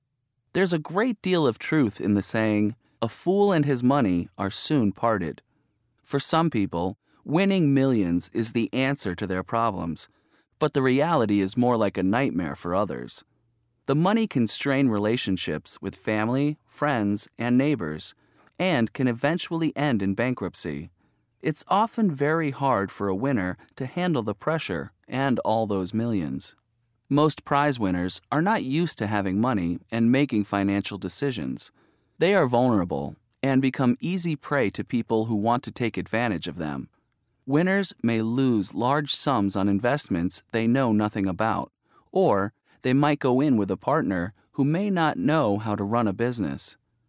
قطعه قرائيه جاهزه للصف الاول الثانوي ف2 الوحده الرابعه mp3